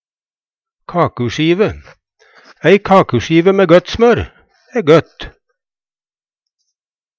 kakusjive - Numedalsmål (en-US)